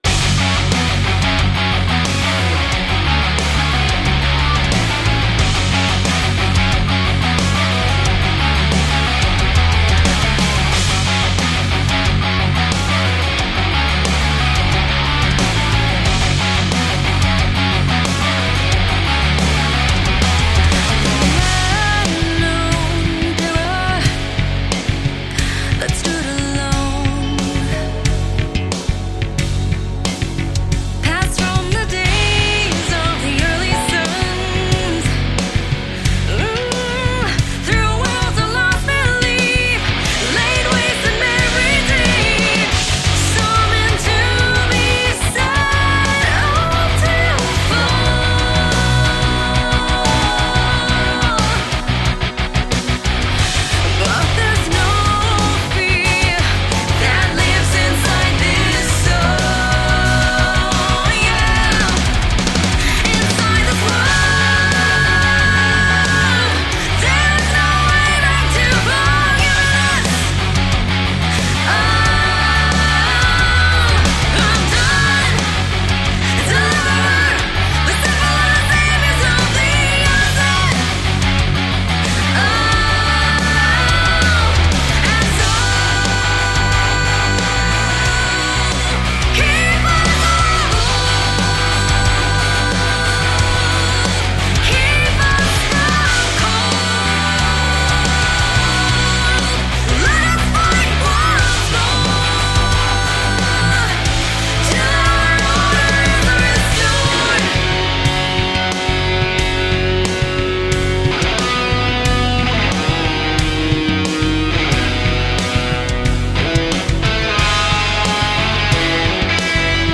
Shreddage 3 Hydra是一款8弦重型吉他音源，由Impact Soundworks出品，适用于Kontakt Player。
有两个不同的拾音器（bridge和neck），可以切换或混合，提供不同的音色。
支持多种技巧，如palm mute, tremolo, harmonics, pinch squeals, tapping, legato, slides, vibrato, bends, unison bends, strumming, chords, hand mute, pick noise等。
有超过50个预设，包括清音、领奏、节奏、放克、蓝调、金属等风格，还可以自己创建和保存预设。